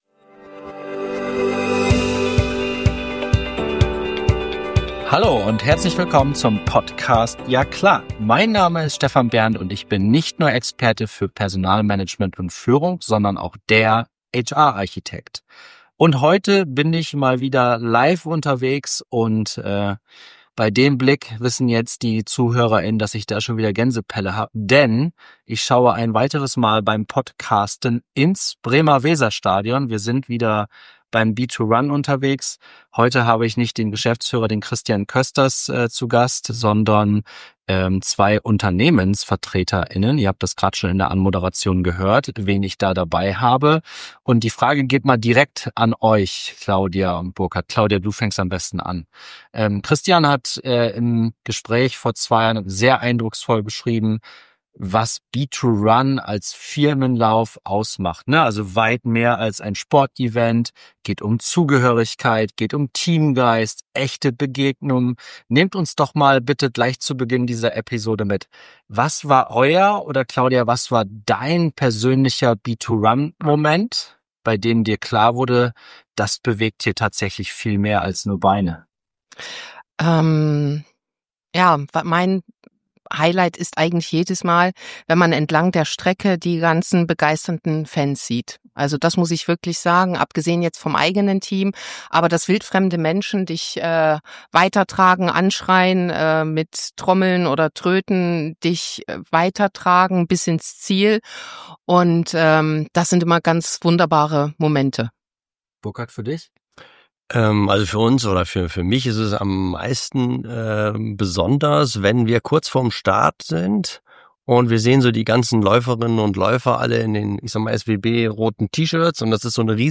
In dieser Interviewfolge des Ja klaHR! Podcasts